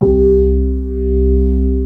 B3-ORGAN 4.wav